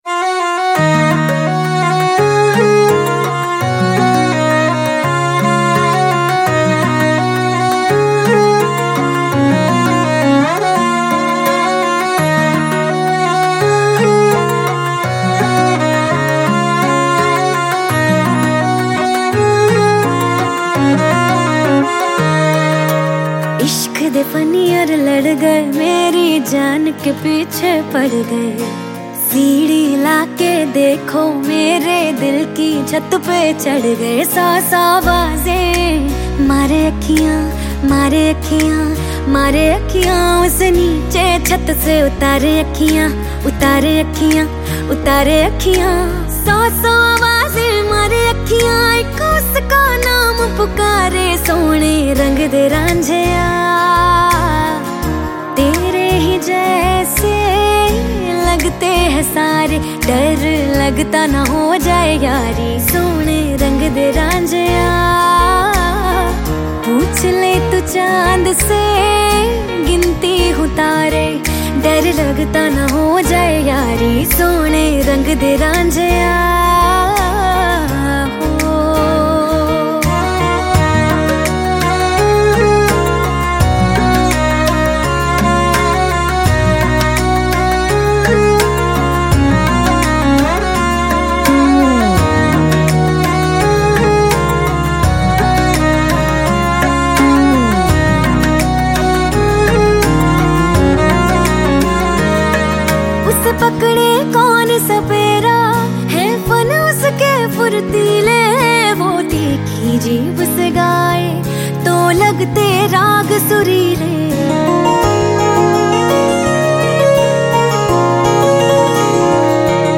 Bollywood Mp3 Music 2017